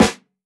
• Steel Snare Drum A Key 55.wav
Royality free steel snare drum sample tuned to the A note. Loudest frequency: 1498Hz
steel-snare-drum-a-key-55-Cna.wav